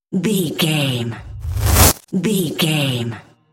Trailer raiser
Sound Effects
Fast paced
In-crescendo
Atonal
futuristic
tension
riser